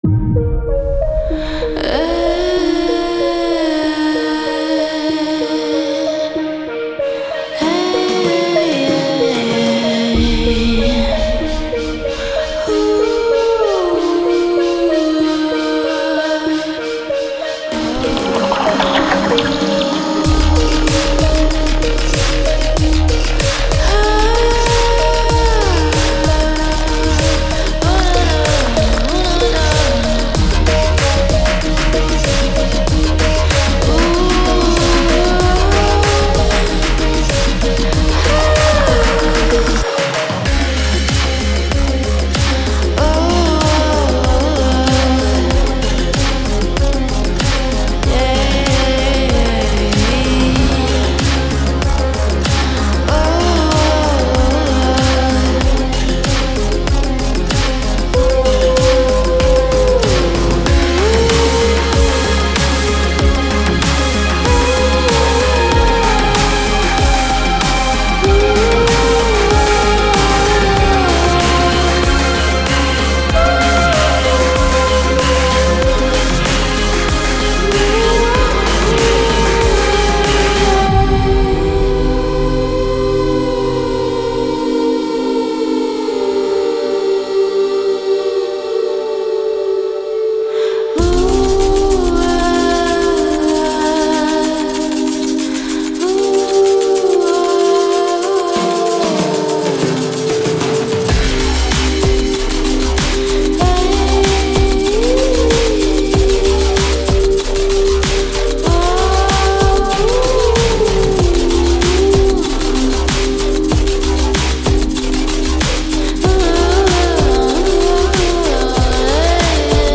“Future Synthwave”-Synthwave是一种源于80年代音乐的流派，如果您将复古声音与现代风格相结合会
自然声音充满您的混音的一种好方法。
.009 x-(80s Clap Loops)
.010x-(Melodic Loops)-(Dry/Wet)